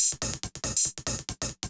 Index of /musicradar/ultimate-hihat-samples/140bpm
UHH_ElectroHatC_140-03.wav